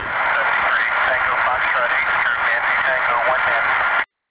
Hear His Signal in Rome!